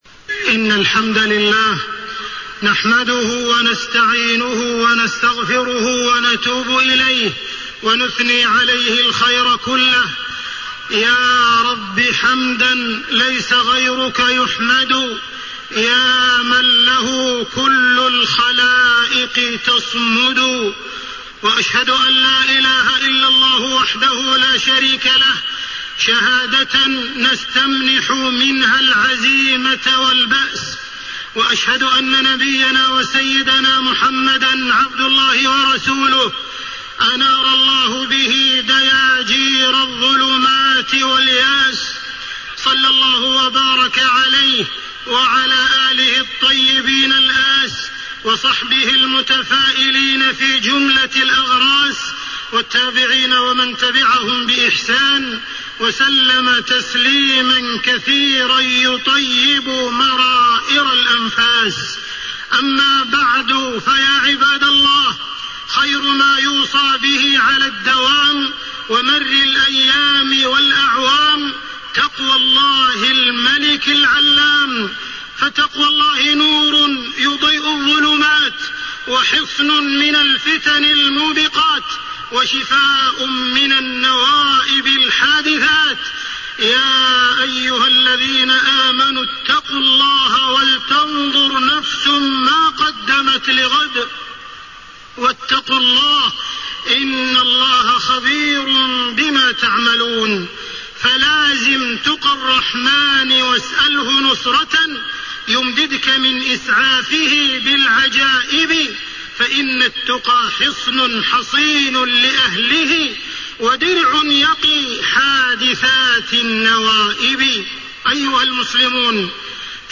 تاريخ النشر ٢ محرم ١٤٣٤ هـ المكان: المسجد الحرام الشيخ: معالي الشيخ أ.د. عبدالرحمن بن عبدالعزيز السديس معالي الشيخ أ.د. عبدالرحمن بن عبدالعزيز السديس الأمل والتفاؤل بقدوم عام جديد